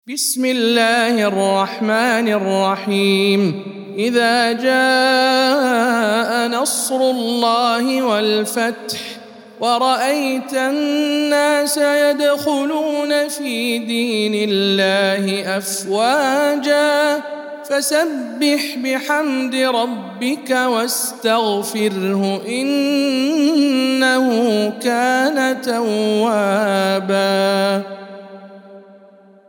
سورة النصر - رواية الدوري عن الكسائي